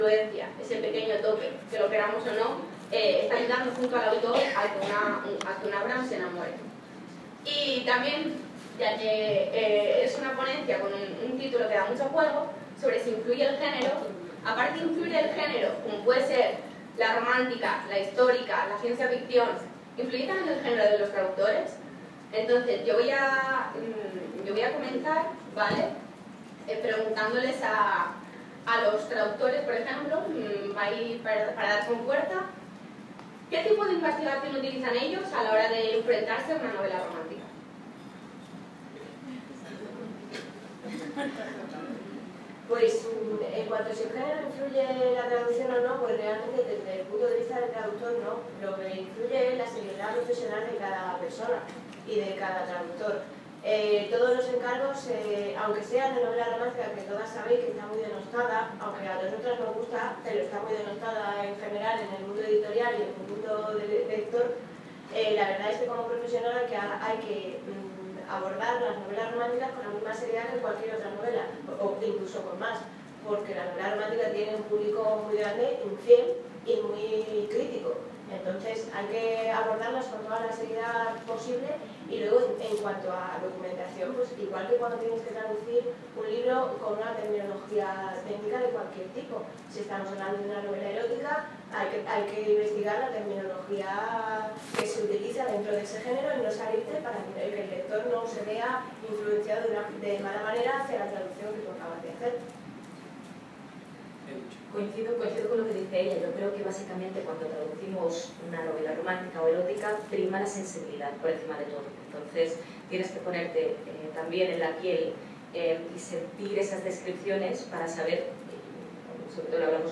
La relación entre lector y traductor: mesa redonda sobre la traducción de género romántico
Así sucedió en noviembre de este año en las Jornadas Ándalus Románticas 2015 que tuvieron lugar en Málaga. Dos días de charlas y mesas redondas relacionadas con el género romántico y erótico al que me invitaron, junto a otros tres traductores, para hablar de la traducción de este tipo de novelas.